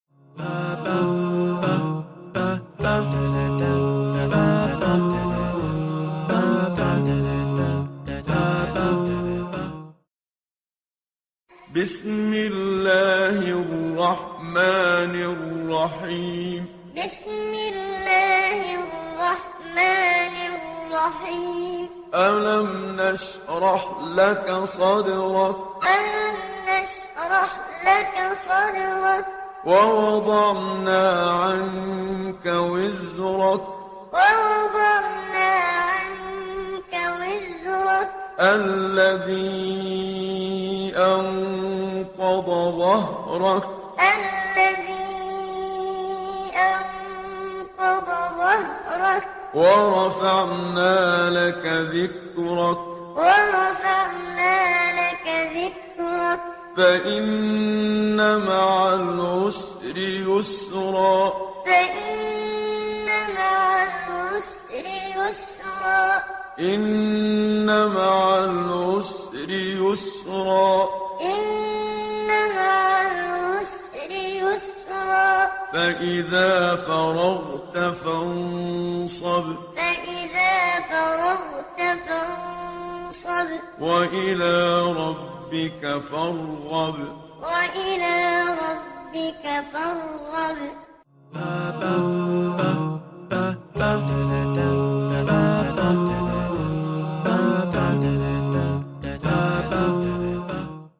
094 - Ash-Sharh - Qur'an Time - Read Along.mp3